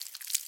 Sound / Minecraft / mob / silverfish / step3.ogg
step3.ogg